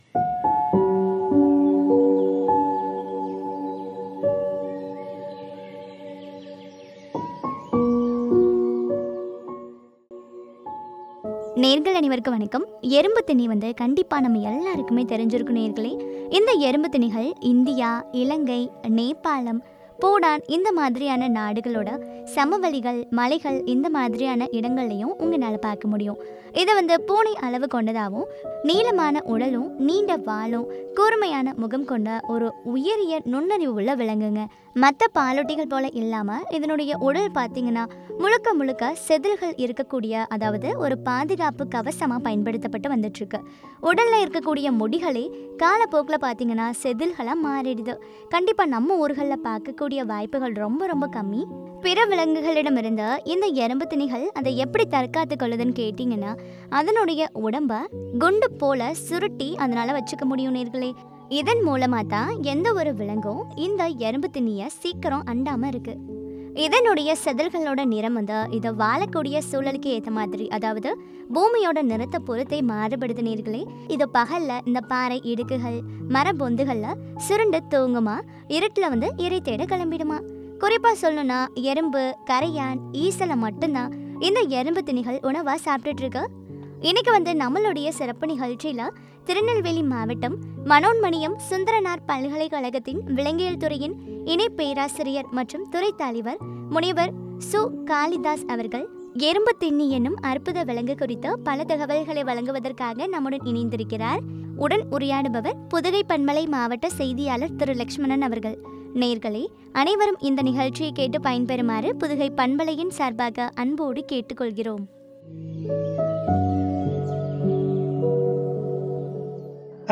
அலுங்கு எனும் அற்புதம் விலங்கு குறித்த உரையாடல்.